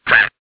One of Toad's voice clips in Mario Kart DS